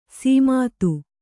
♪ sīmātu